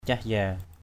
/cah-ya:/ 1.